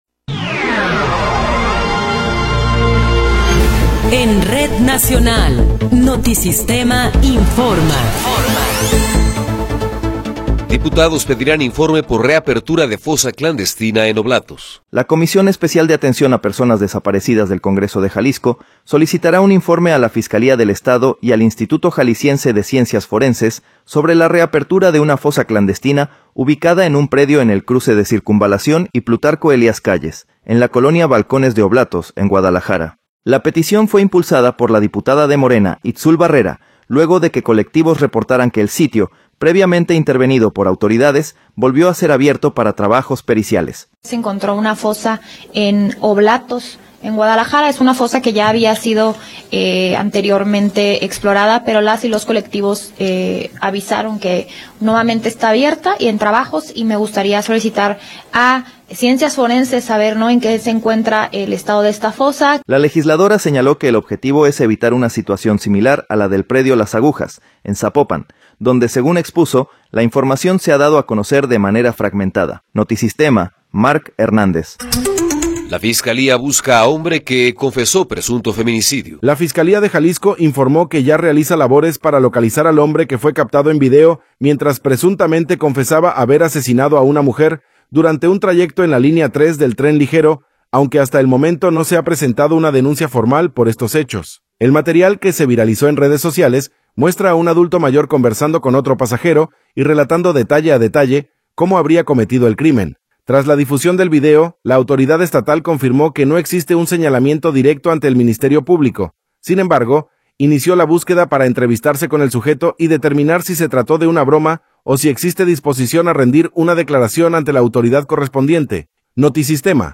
Noticiero 12 hrs. – 12 de Febrero de 2026
Resumen informativo Notisistema, la mejor y más completa información cada hora en la hora.